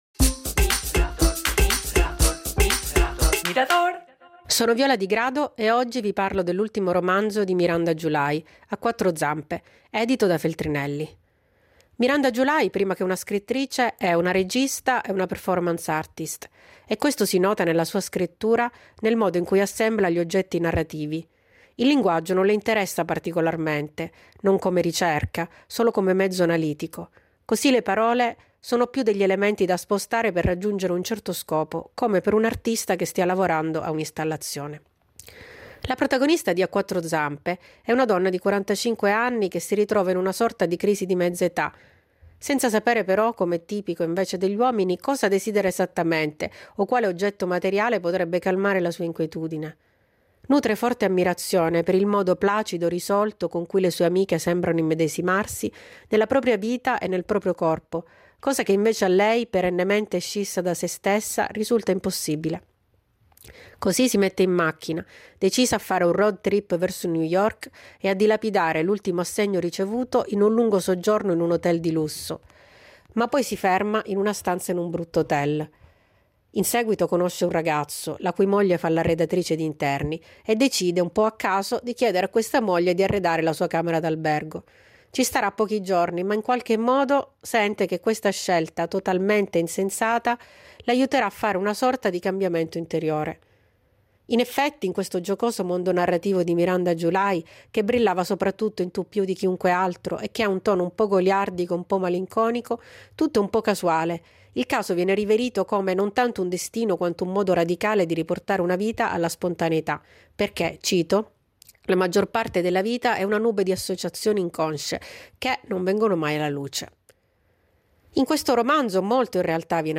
Libro recensito